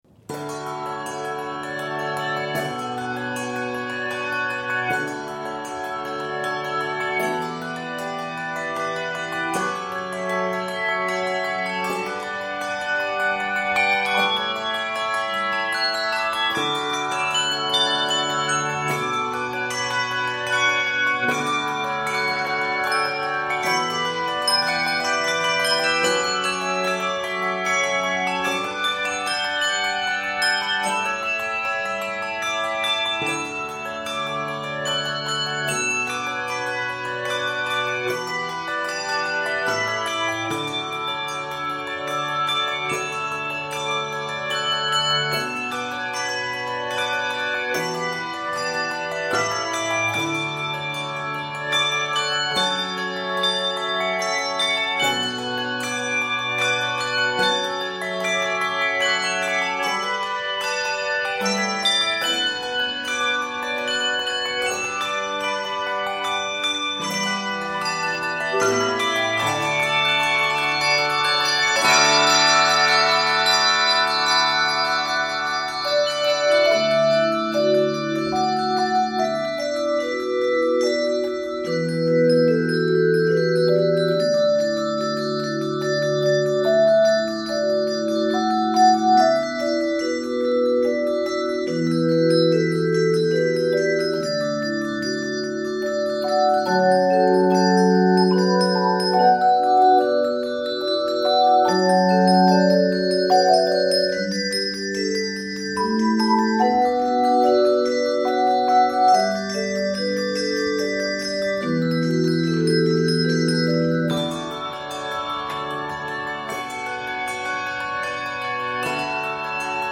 With constant flowing sixteenth notes
a refreshing and meditative setting
Keys of D Major or Eb Major.